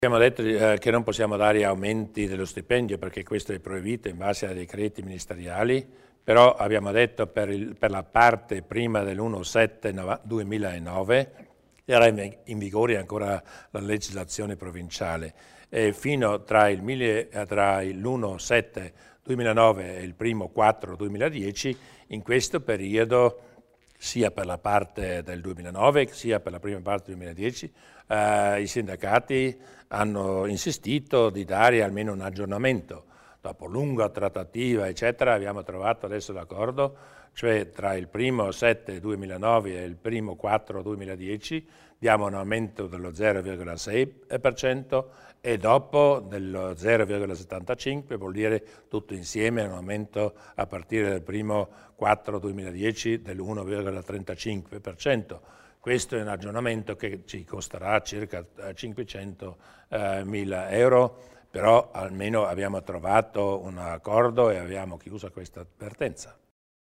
Il Presidente Durnwalder illustra le novità per i contratti degli insegnanti